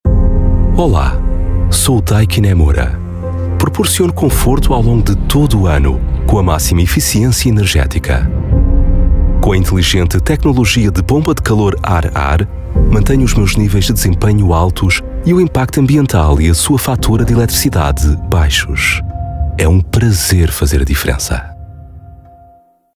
Voice Samples: Commercial Reads
male